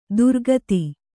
♪ durgati